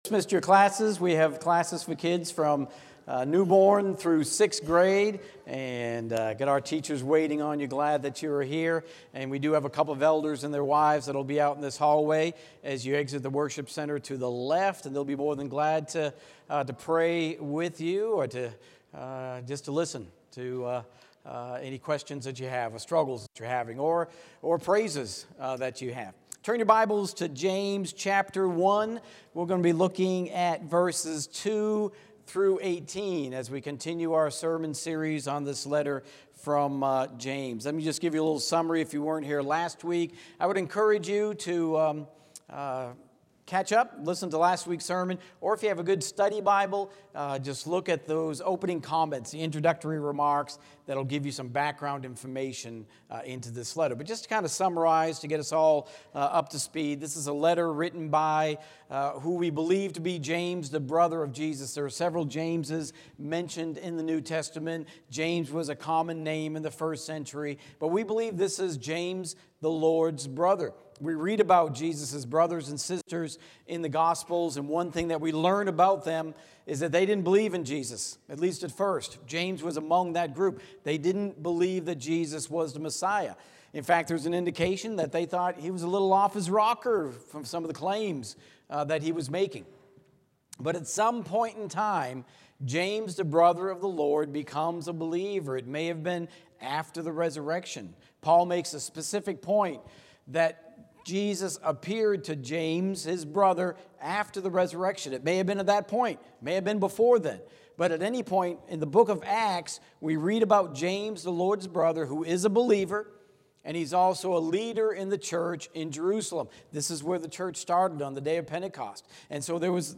Sermons | Park Avenue Church of Christ